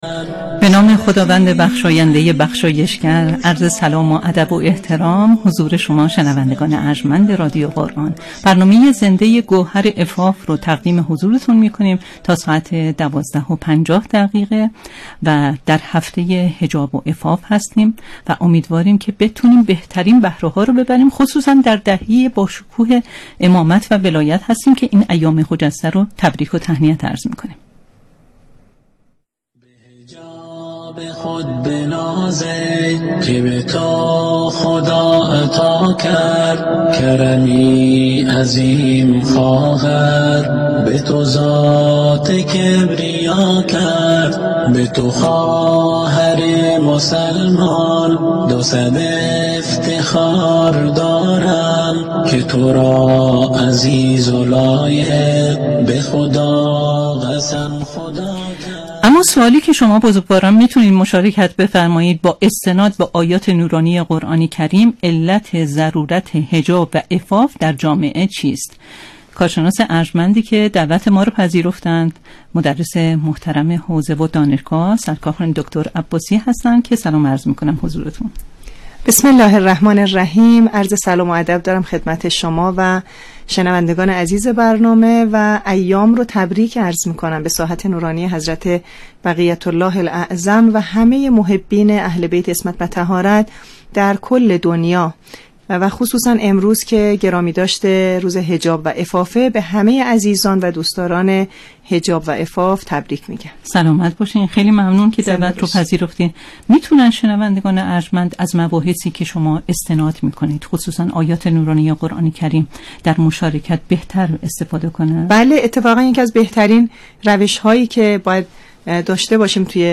ویژه‌برنامه زنده «گوهر عفاف» امروز، 21 تیرماه از شبکه رادیویی قرآن پخش شد.